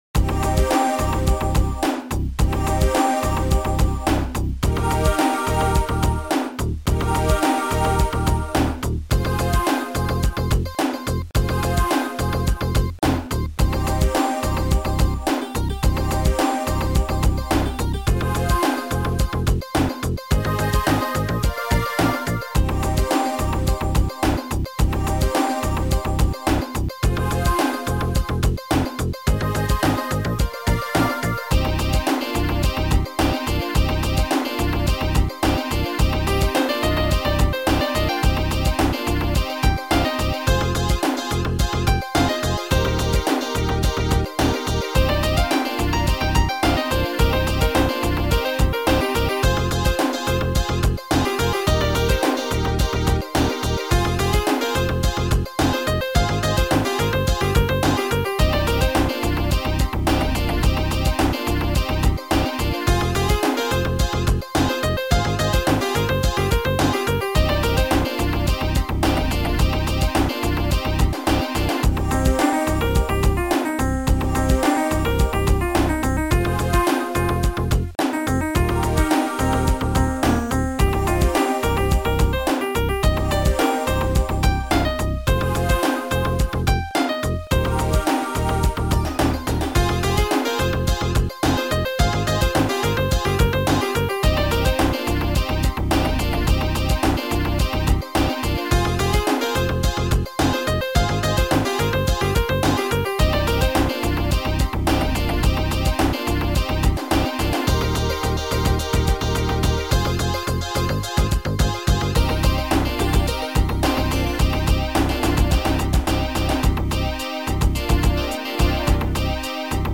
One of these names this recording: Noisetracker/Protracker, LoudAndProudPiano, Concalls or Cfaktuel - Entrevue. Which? Noisetracker/Protracker